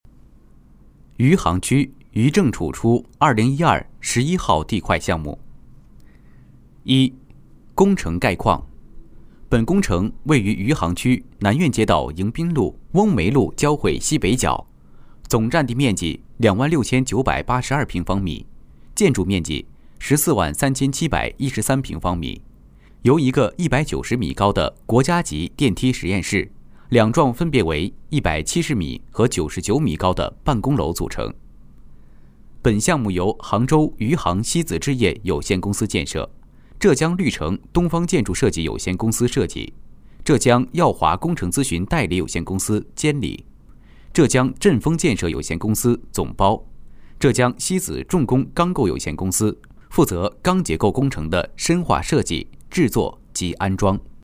工程解说男109号
轻松自然 规划总结配音
年轻活力中音男配，项目解说，舌尖美食还不错，可把握不同题材风格。